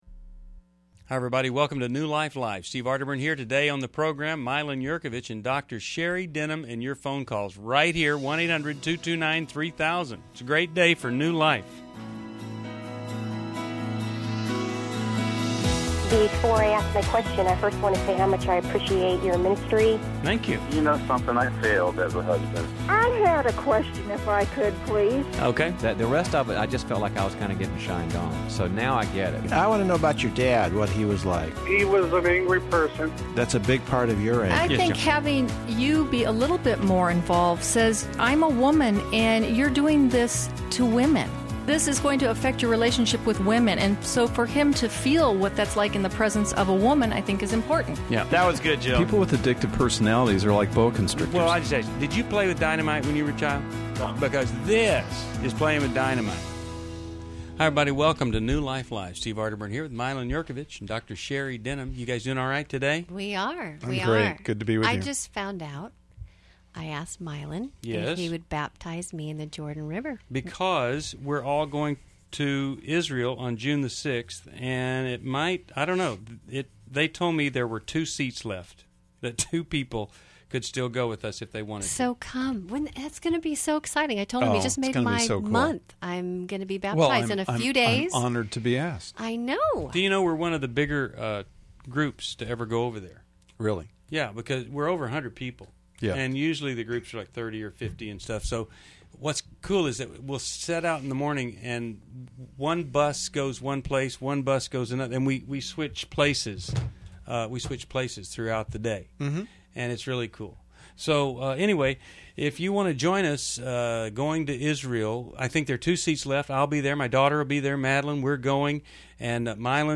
Explore boundaries, separation, and overcoming fear in relationships on New Life Live: May 23, 2011. Join hosts as they tackle listener questions and offer insights.